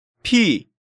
Category:Hangeul sounds